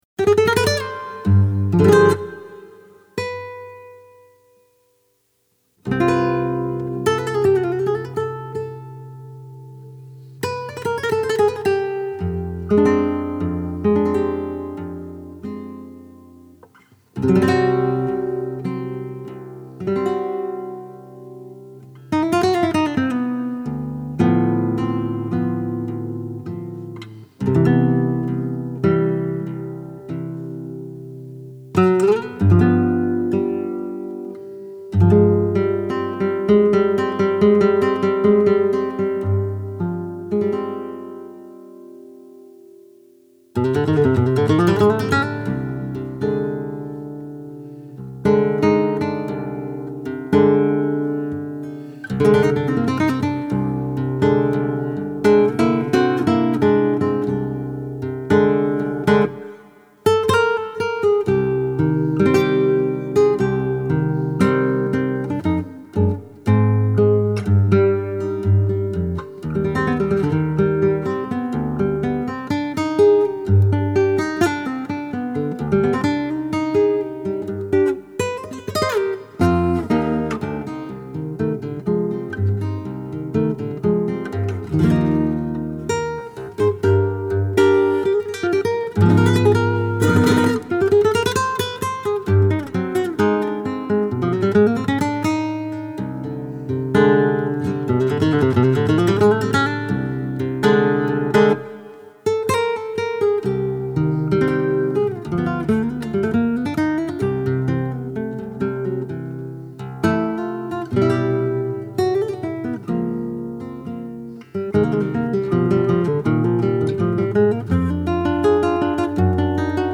composition et guitare